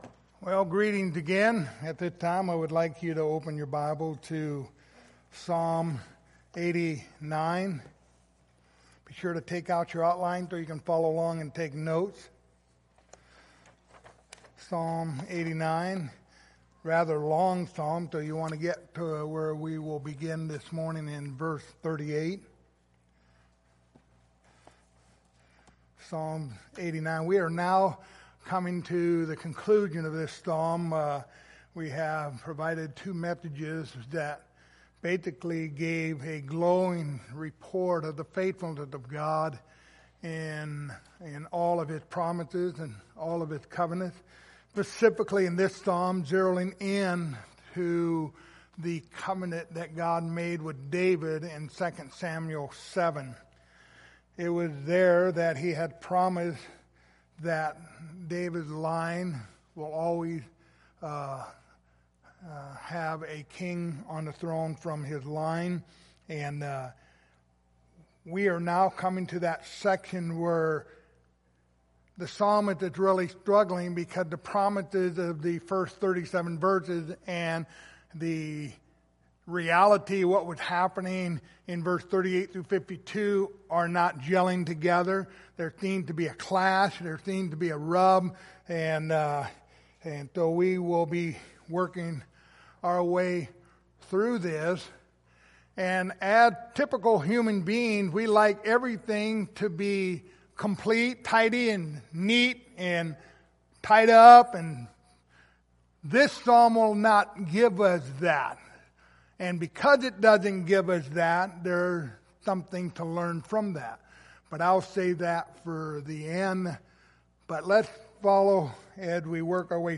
The book of Psalms Passage: Psalms 89:38-52 Service Type: Sunday Morning Topics